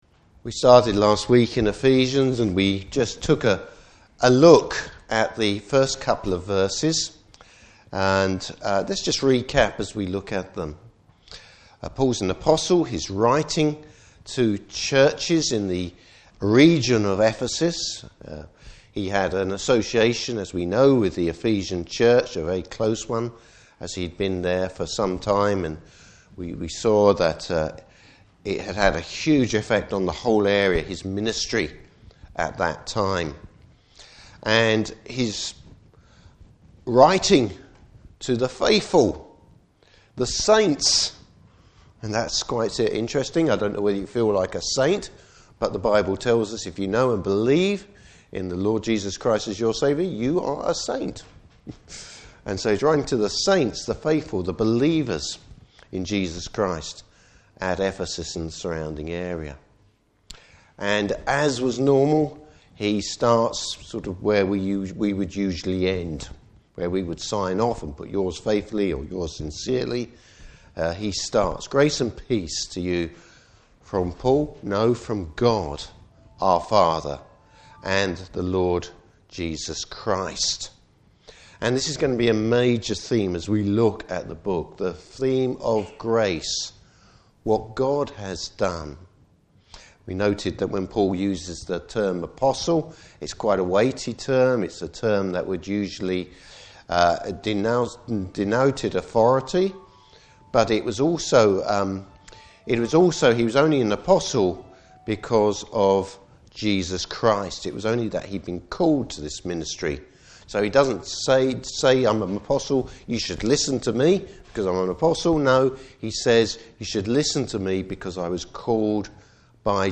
Service Type: Morning Service Bible Text: Ephesians 1:3-14.